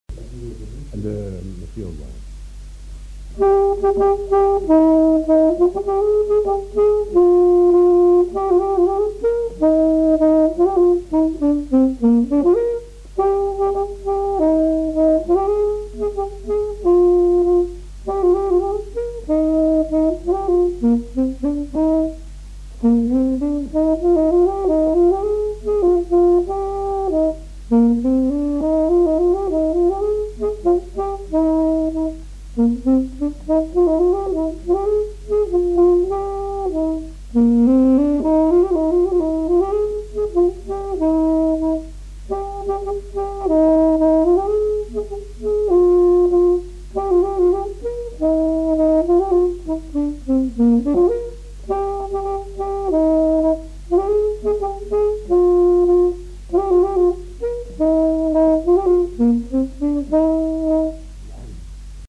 Aire culturelle : Haut-Agenais
Lieu : Lougratte
Genre : morceau instrumental
Instrument de musique : saxophone
Danse : rondeau